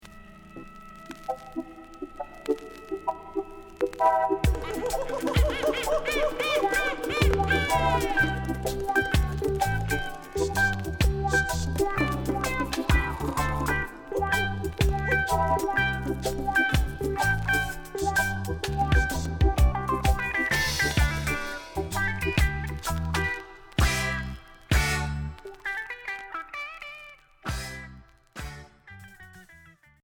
Reggae Premier 45t retour à l'accueil